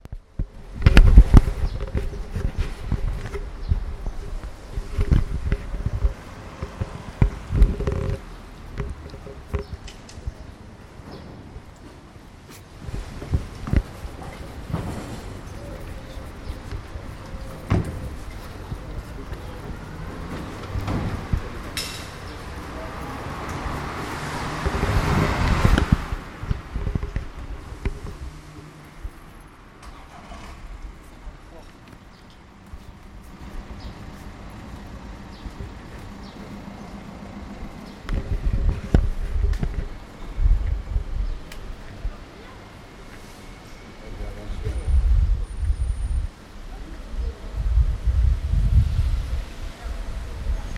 Bruit de la population urbaine ( voiture, travaux etc...)